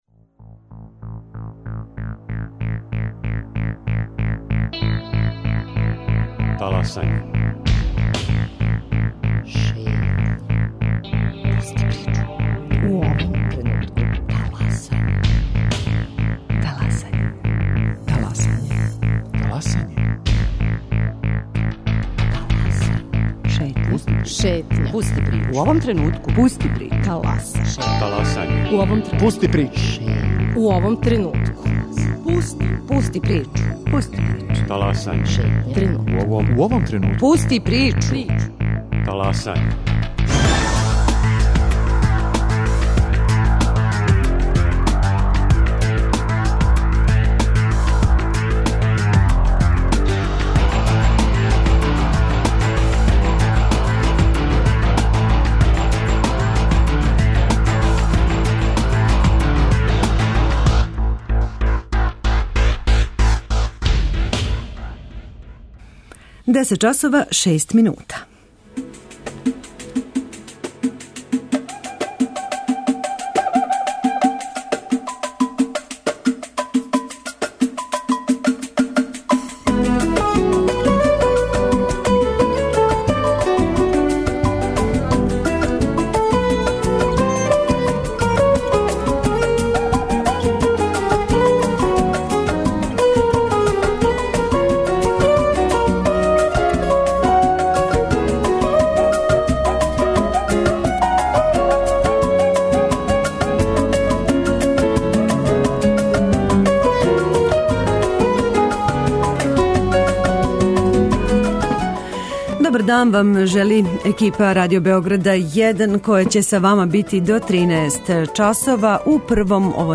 Легенда београдске Чубуре и српског глумишта Драган Николић, и сам међу описанима у књизи, читаће једну од прича свог пријатеља Тиркета.